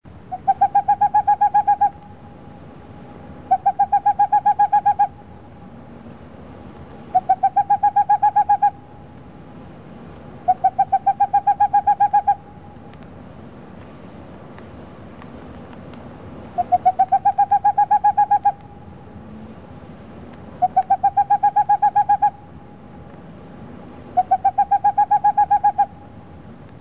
Boreal Owl
Boreal Owl.